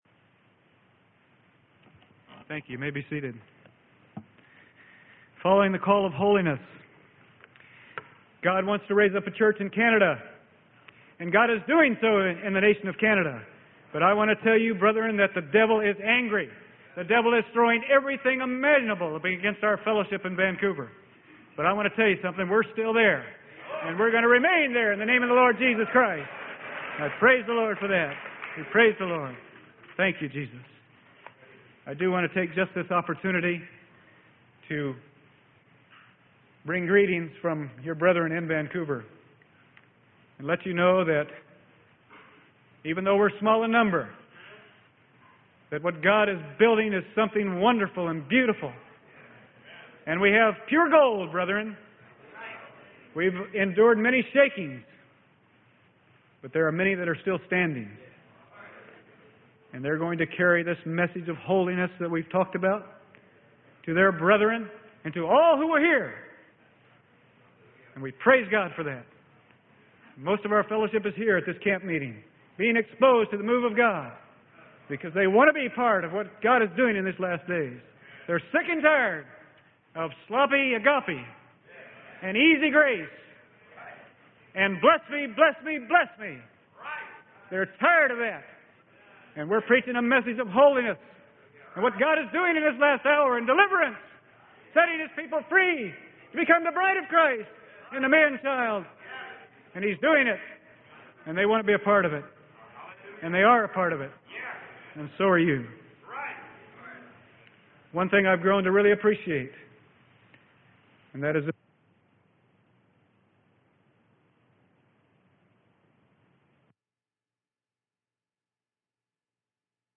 Sermon: BECOMING AS A LITTLE CHILD: AN ATTRIBUTE OF THE MANCHILD.
BECOMING AS A LITTLE CHILD: AN ATTRIBUTE OF THE MANCHILD. Fall 1984 Camp Meeting.